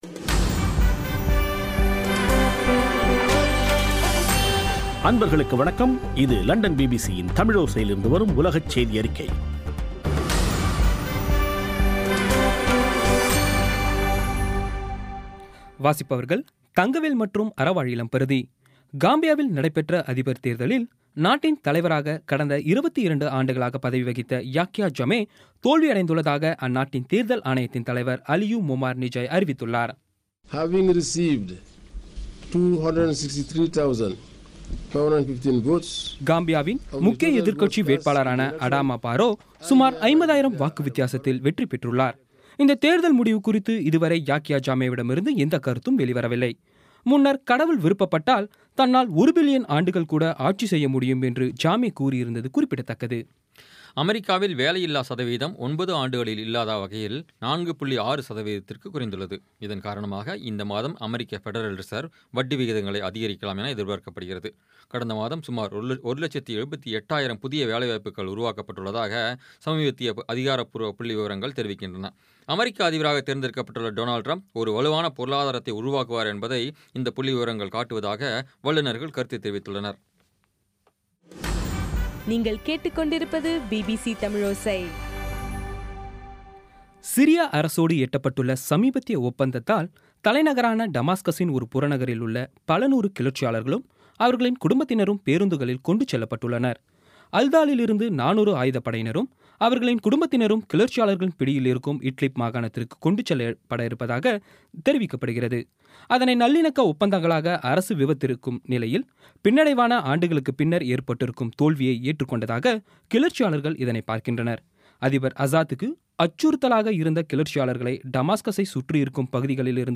பி பி சி தமிழோசை செய்தியறிக்கை (02/12/16)